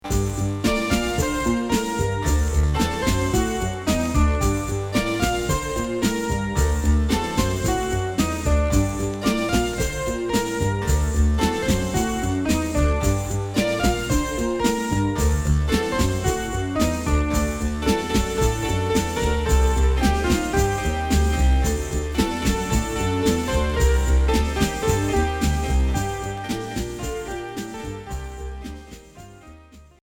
Minimal synth Unique 45t retour à l'accueil